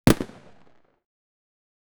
Pyrotechnics Sound Effects - Free AI Generator & Downloads
firework---single-explosi-wczjoykf.wav